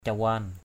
/ca-wa:n/ (d.) chung, chén nhỏ = petite tasse à alcool. cawan alak cwN alK chung rượu.